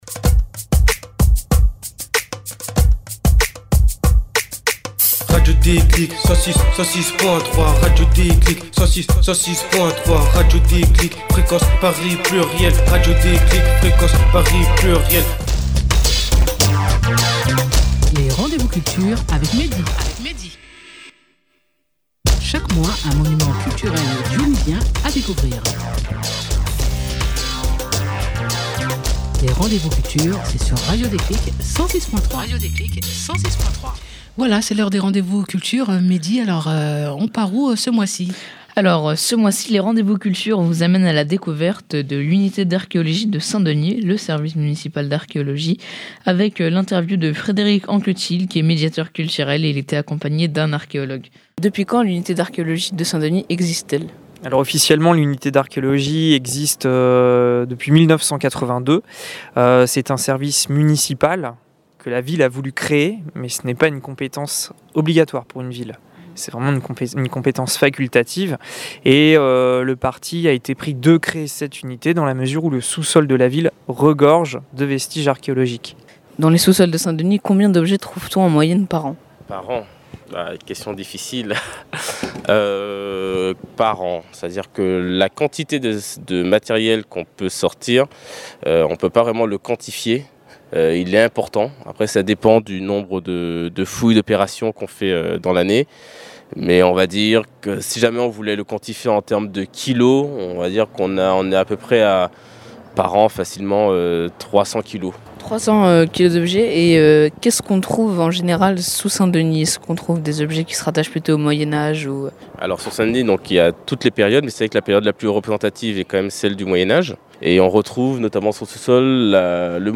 Jouez à vous cultiver sur Radio Déclic 106.3FM avec les RDV Culture.